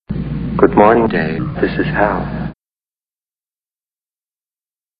Movie Sound Bites